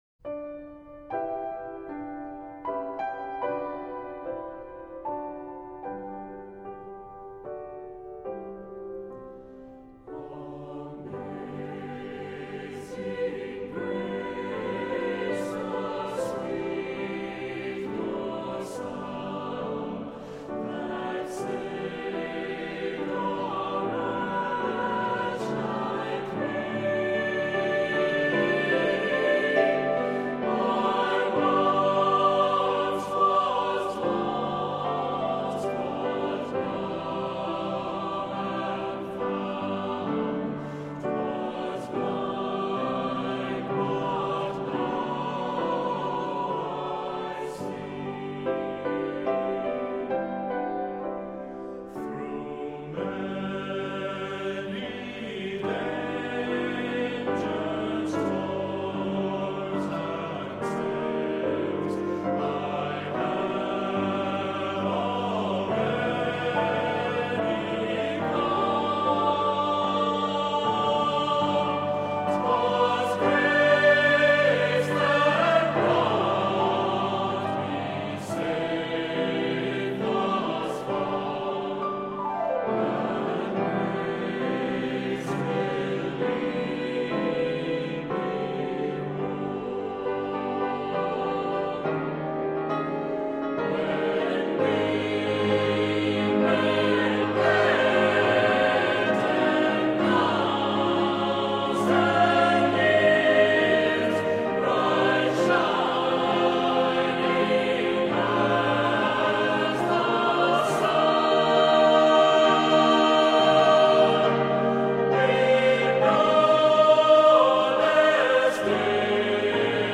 Accompaniment:      With Piano
Music Category:      Christian